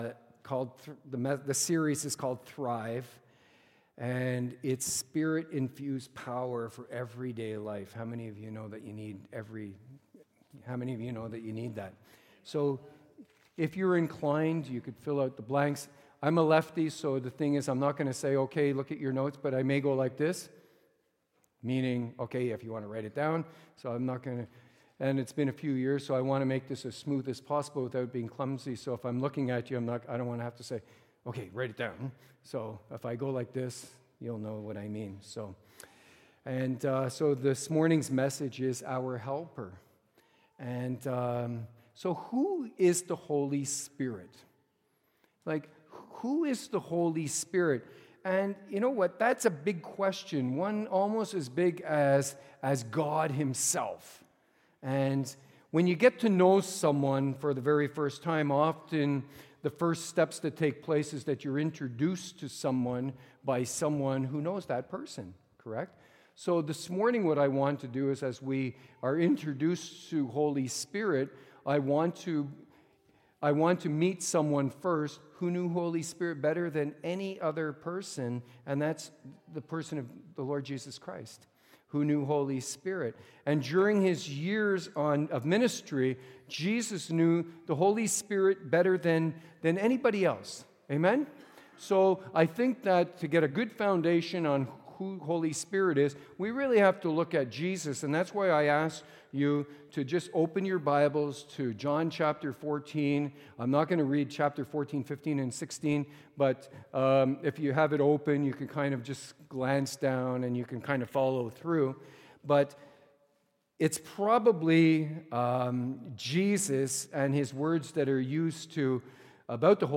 Sermons | Gateway Church